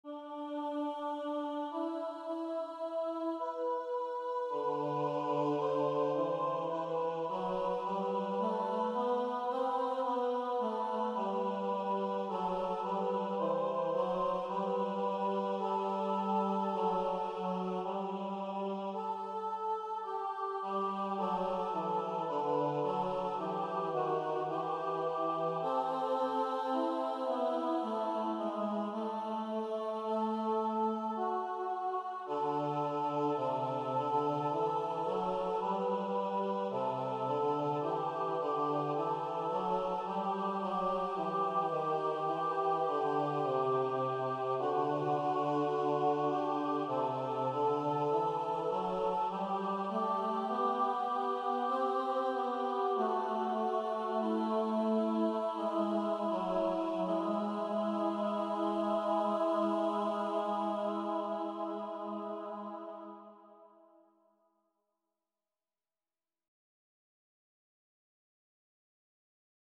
Somewhat rearranged from the 2-part original, this setting of "Adoramus Te, Christe"/"We Adore Thee, O Christ" (Antiphon at the Transfer of the Blessed Sacrament on Good Friday) is set for 2-part women's/men's voices, although by transposing the appropriate part, it could be sung by women or men alone. Note that the upper part follows the melody of the Gregorian chant as found in Liber Usualis.